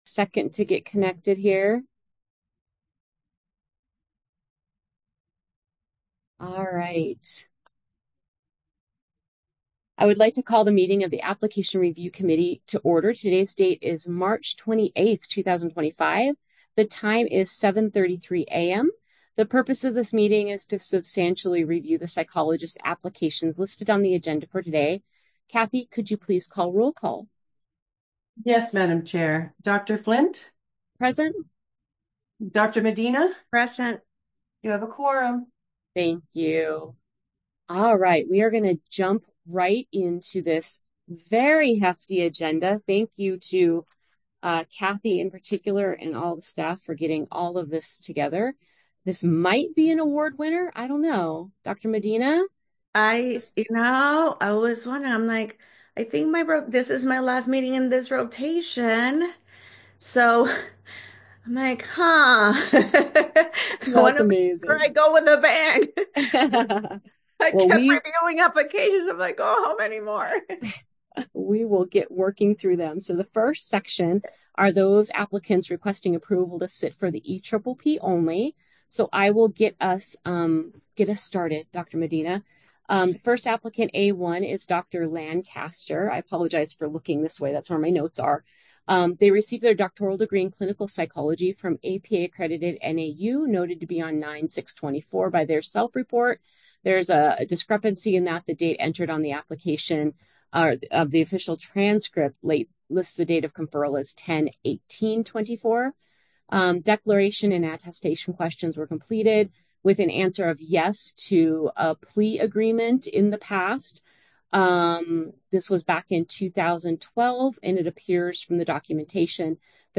Application Review Committee Meeting | Board of Psychologist Examiners
The meeting is being held virtually via Zoom.